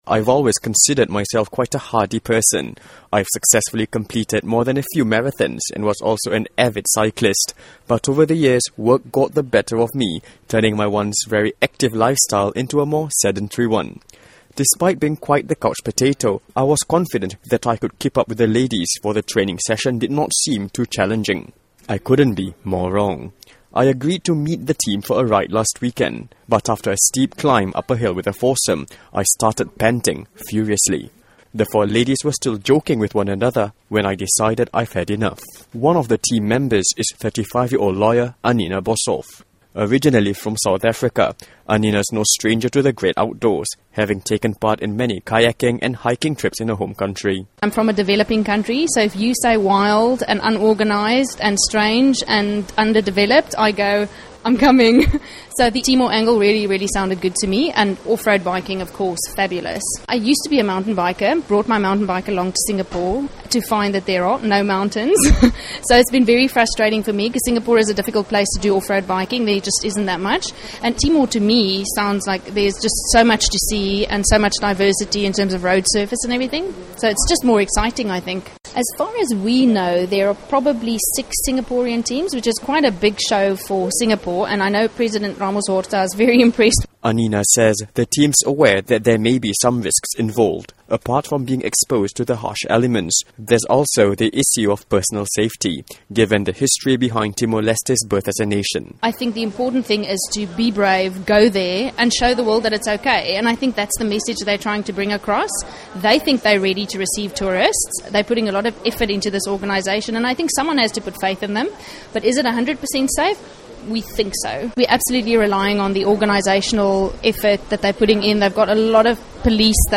938 Live – Post Tour de Timor Interview